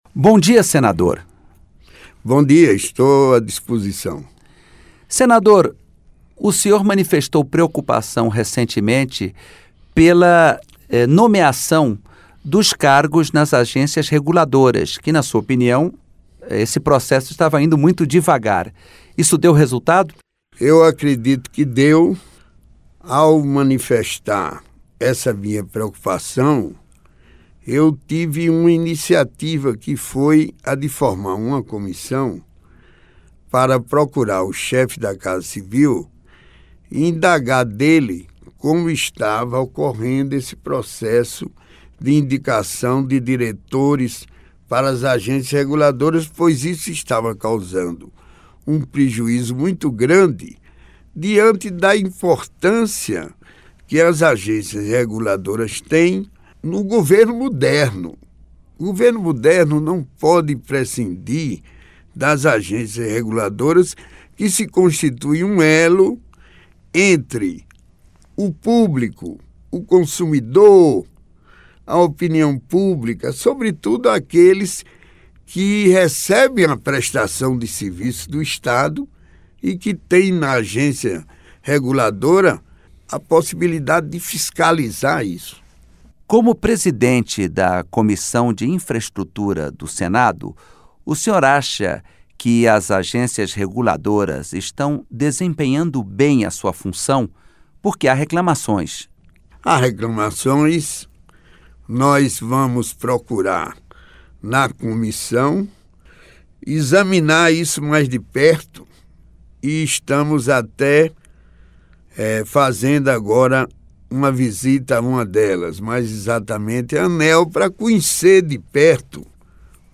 Entrevista com o senador Garibaldi Alves Filho (PMDB-RN).